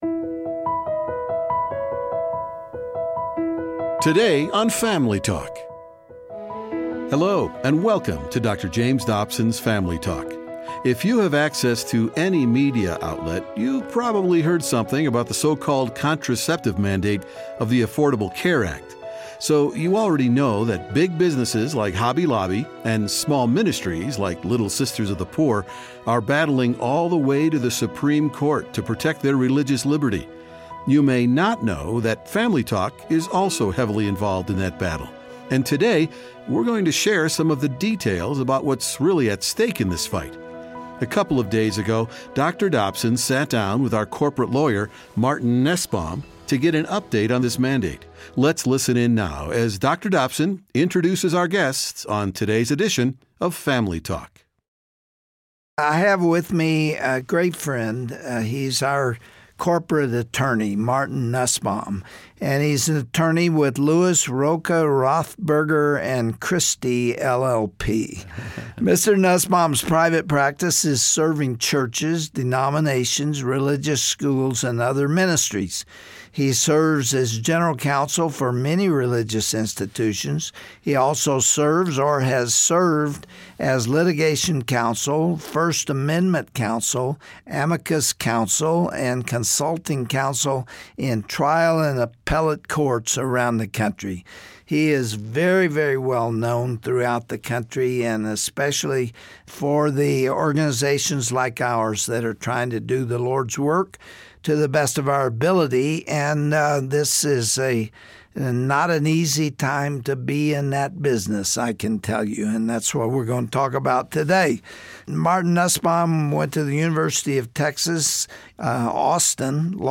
You dont want to miss this revealing conversation on todays edition of Dr. James Dobsons Family Talk.